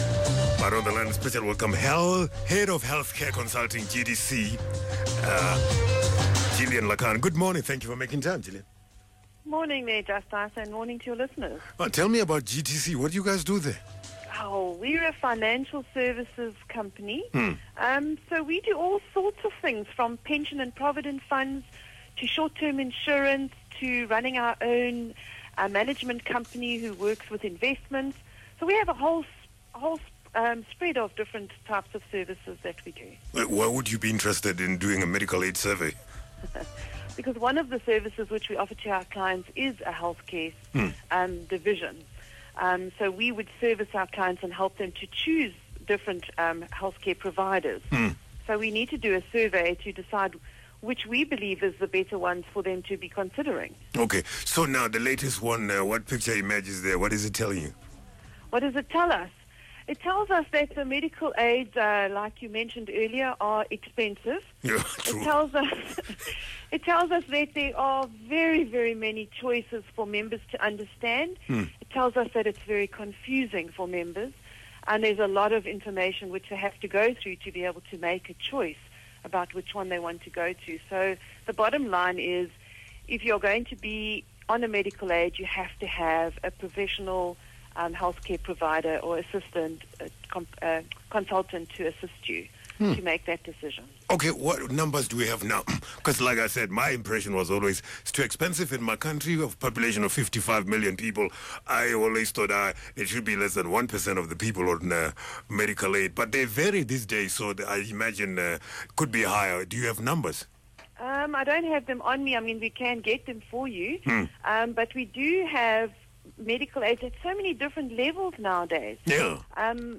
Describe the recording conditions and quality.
is interviewed about medical aids on the Radio 2000 breakfast show.